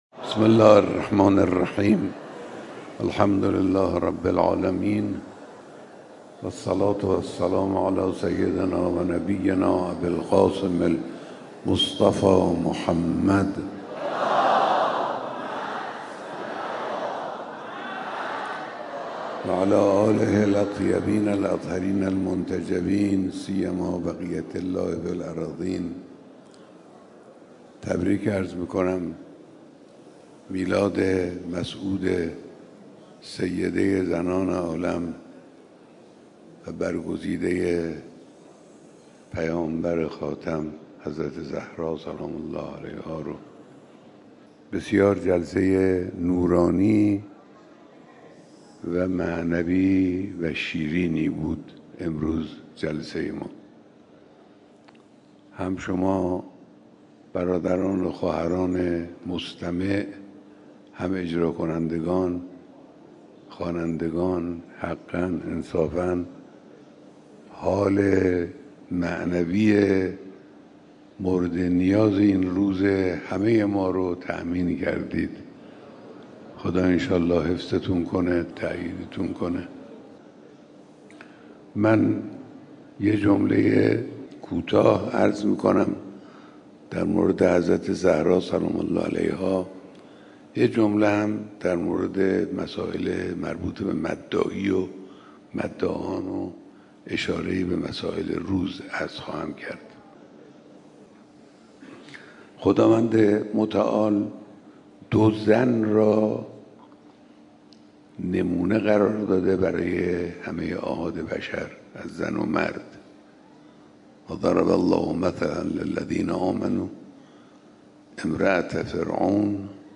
صوت کامل بیانات صبح امروز رهبر انقلاب در دیدار مداحان اهل‌بیت(ع)